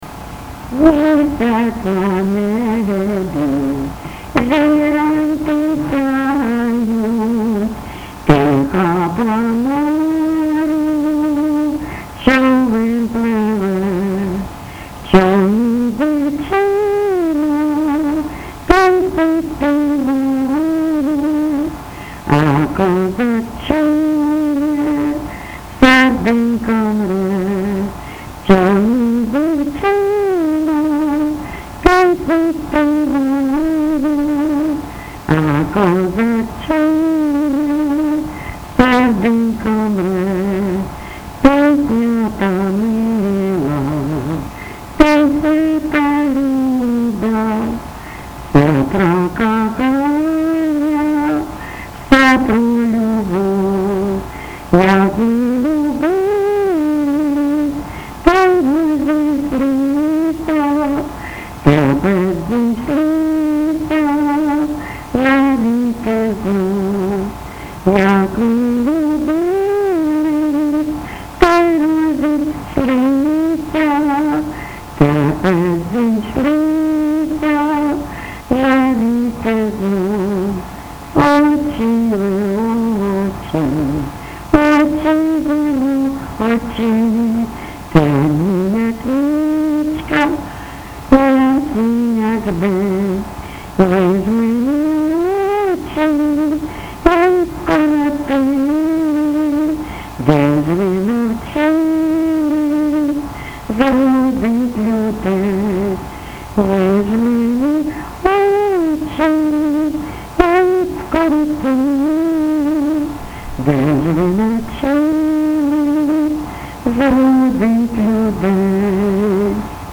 ЖанрРоманси, Пісні літературного походження
Місце записус. Привілля, Словʼянський (Краматорський) район, Донецька обл., Україна, Слобожанщина